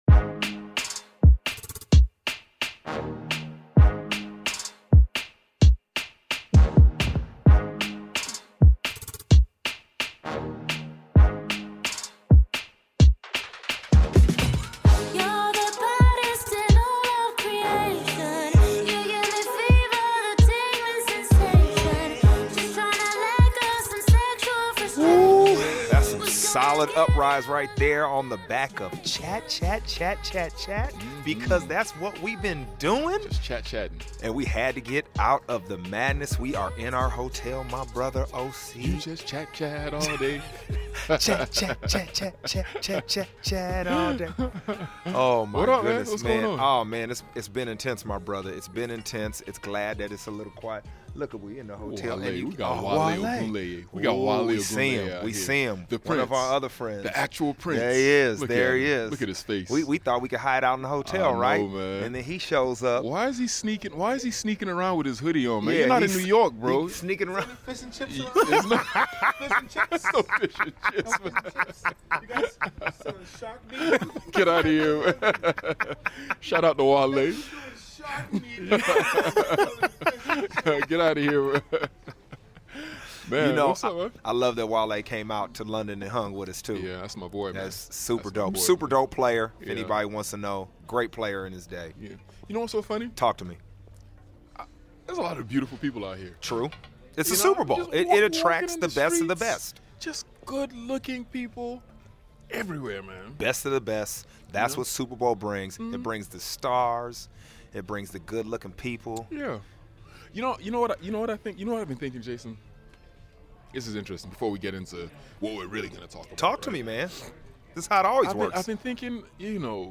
The Uprise FROM ATLANTA where Osi has NOT been partying.